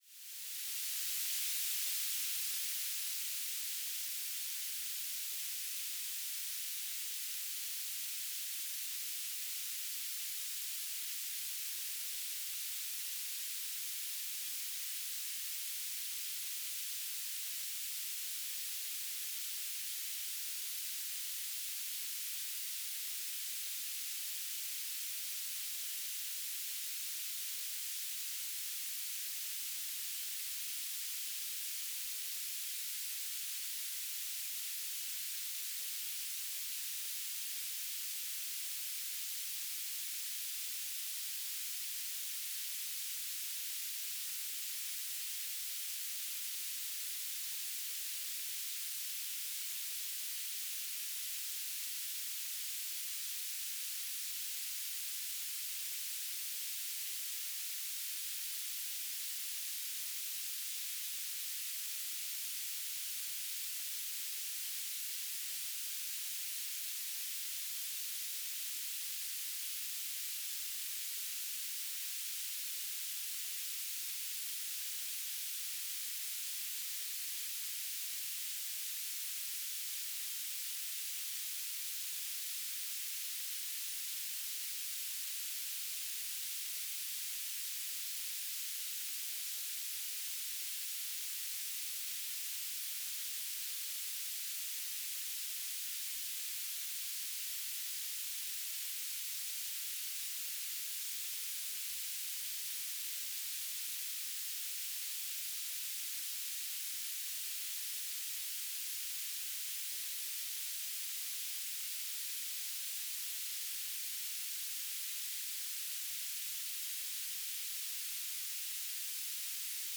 "transmitter_mode": "BPSK",